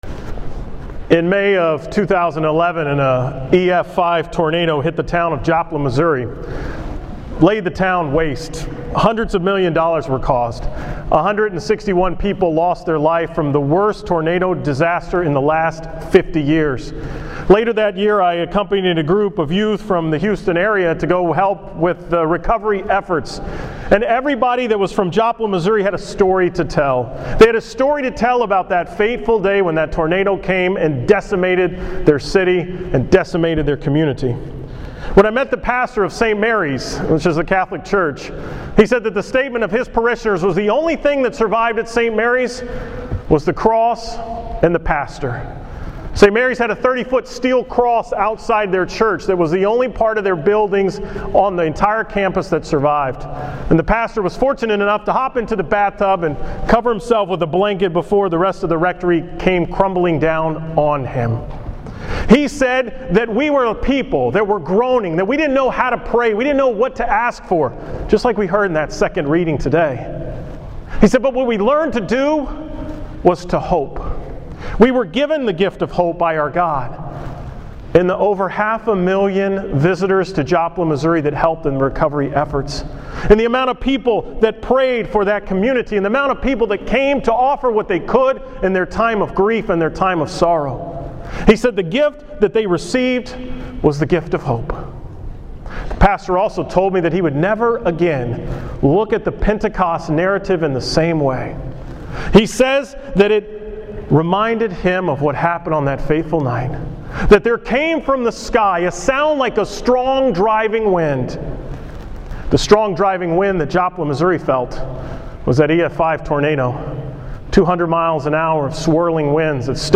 From Pentecost Sunday